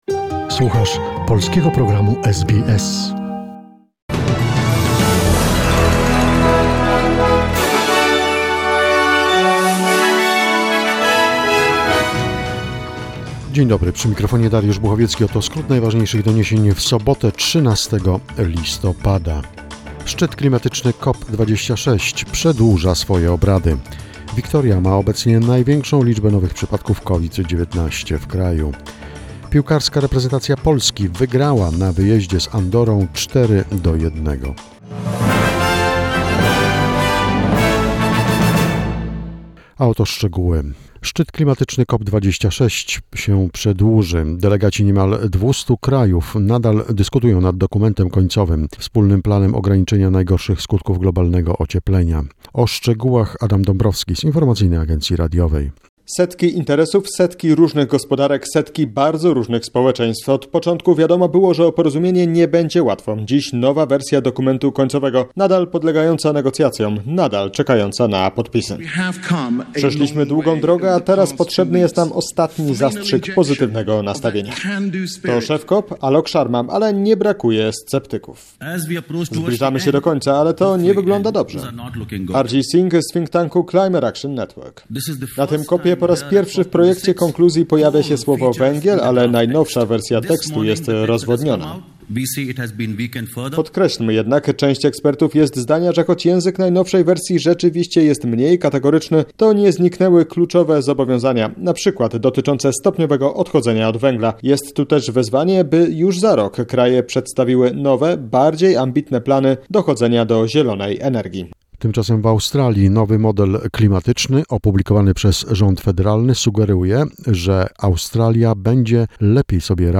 SBS News Flash in Polish, 13 November 2021